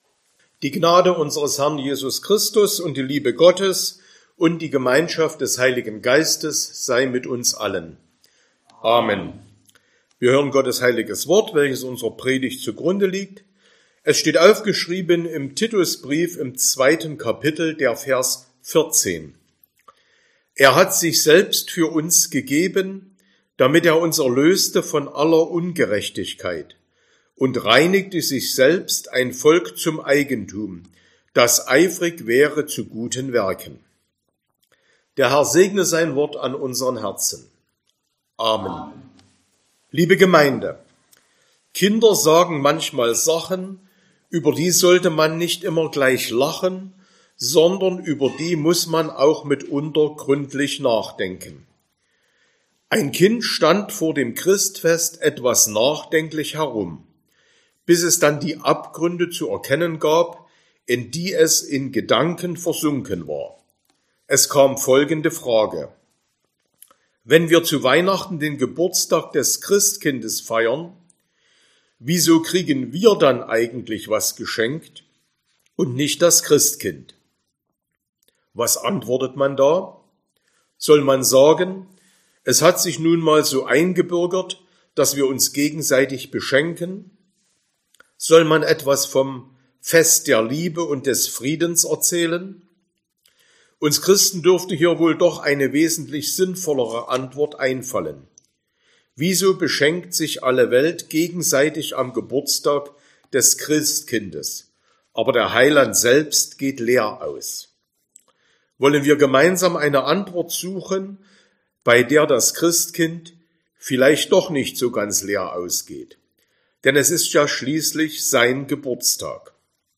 Sonntag nach dem Christfest Passage: Titus 2,14 Verkündigungsart: Predigt « 2.